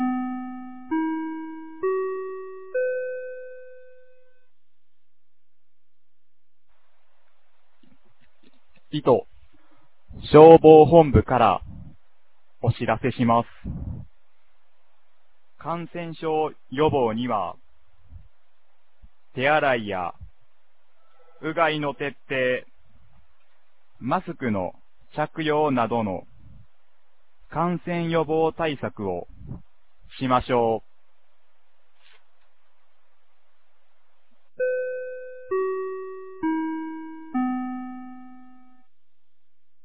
2024年11月25日 10時00分に、九度山町より全地区へ放送がありました。